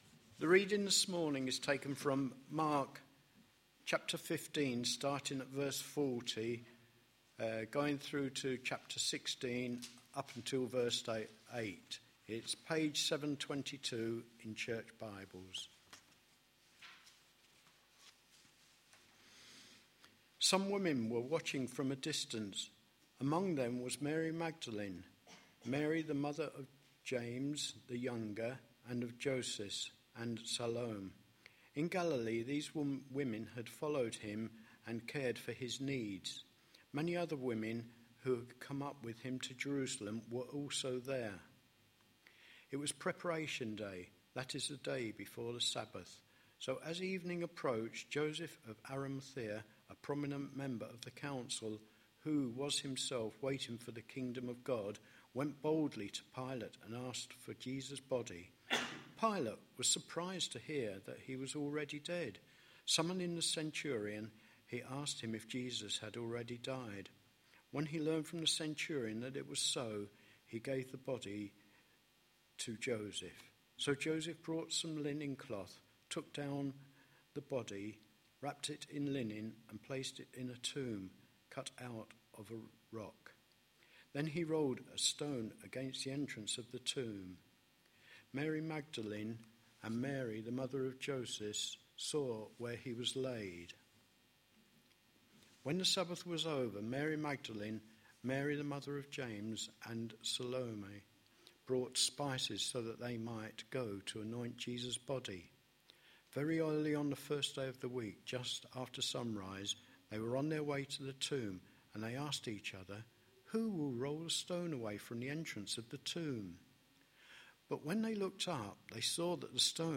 A sermon preached on 8th April, 2012, as part of our Mark series.